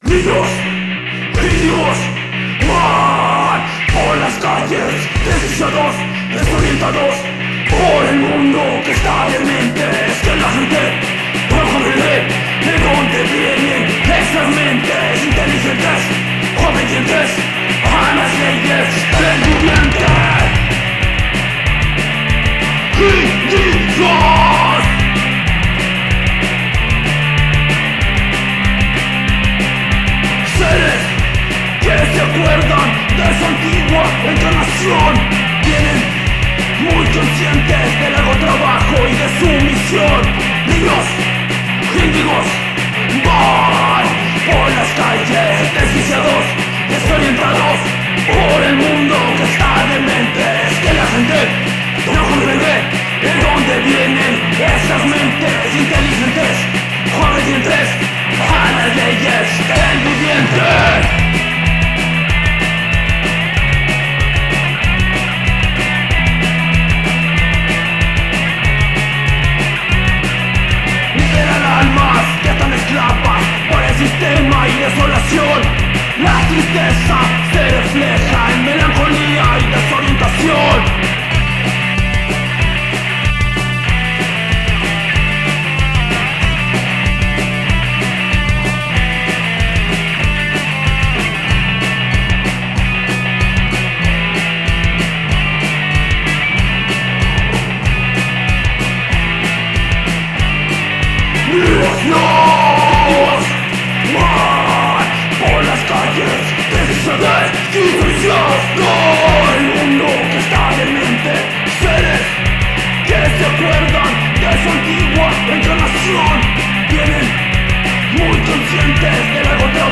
bajo
guitarra, voz
bateria, voz